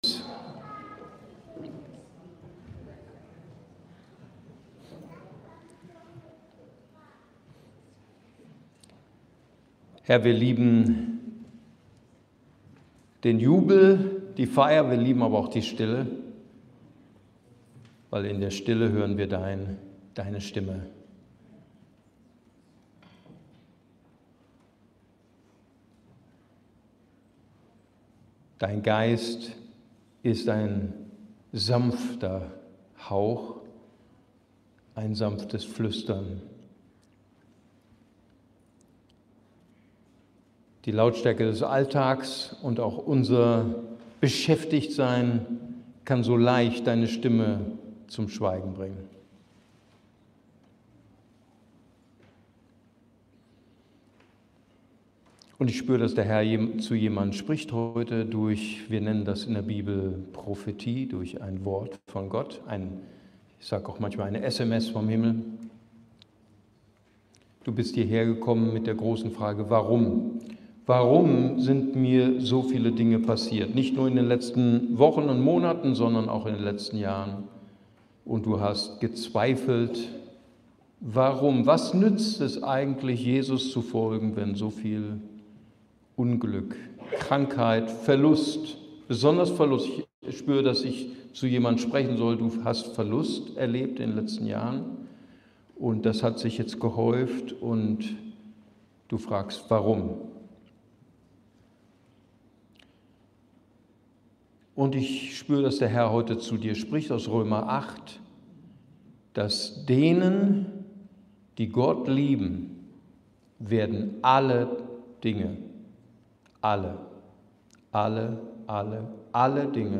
Februar 2026 49 Minuten ICB Predigtreihe Psalm 84 Grenzenlose Freude in Gott!